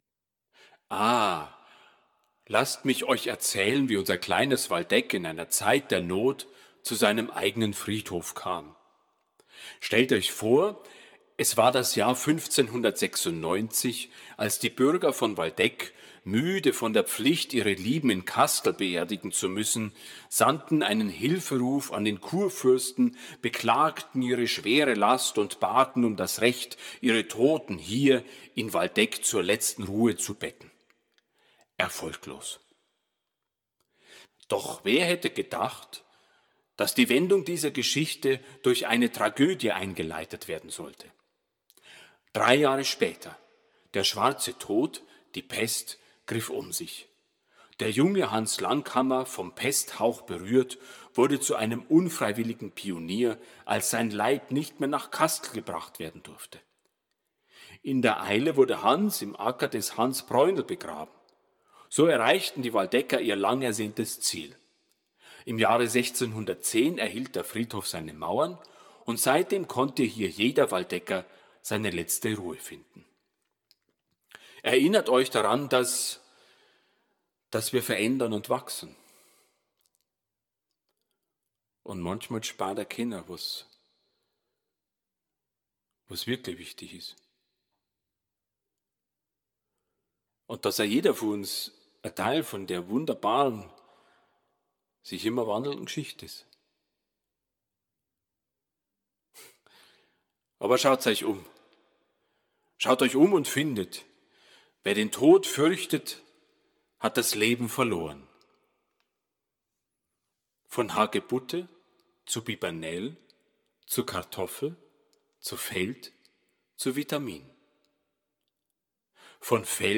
Mit einem leisen Lachen, das fast wie das Rauschen des Windes klingt, verblasst der Geist.